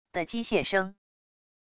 的机械声wav音频